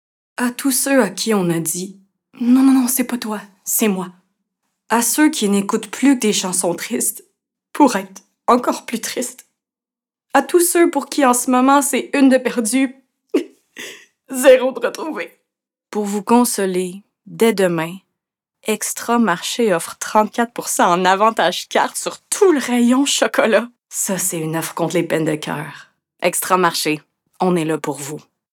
Âge naturelle de la voix Jeune adulte
Timbre Médium - Grave - Petit grain chaleureux
Marché Extra - Personnage typé (dépressive) - Sympathique - Québécois naturel / Publicité